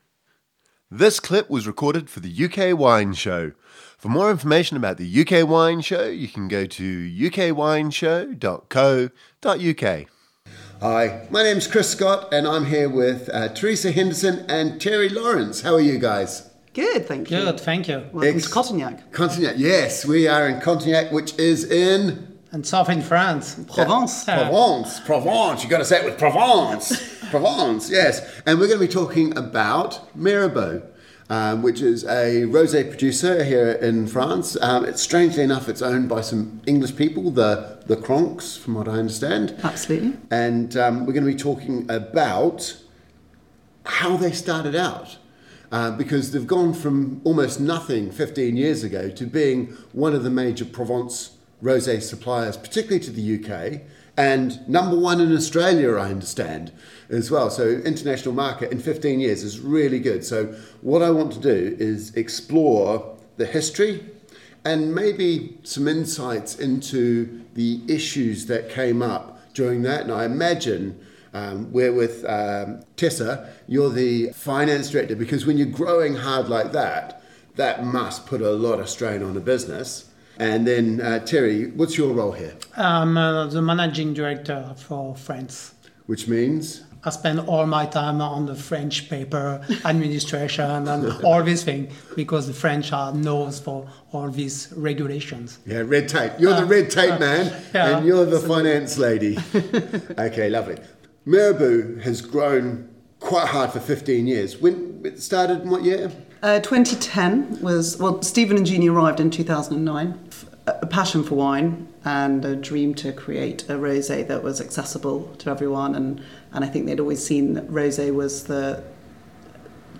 Mirabeau is a leading producer of rosé in Provence - we met up in Cotignac where they operate their original wine boutique.